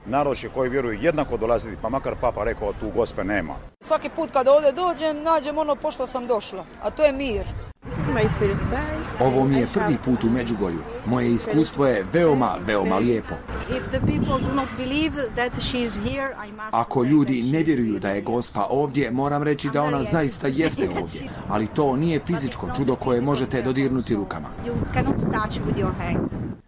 Anketa: Međugorje